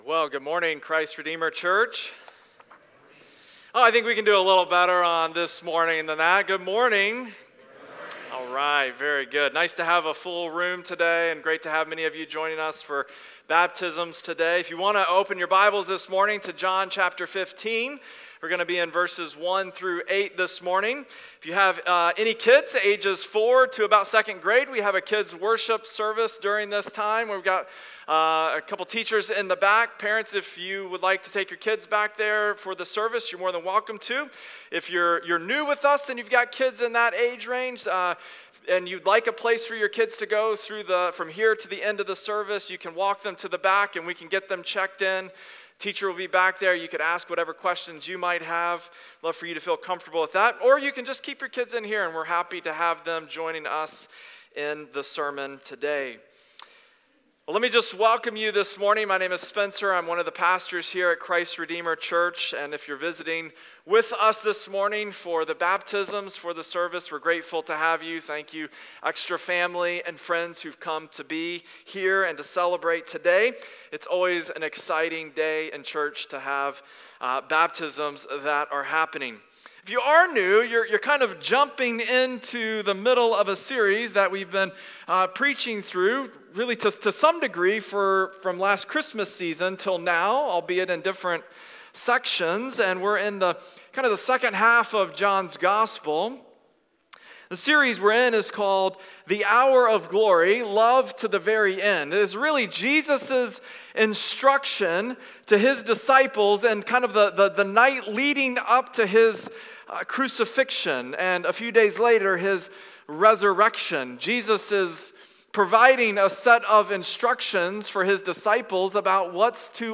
Weekly Sunday Sermons from Christ Redeemer Church in Cottage Grove, MN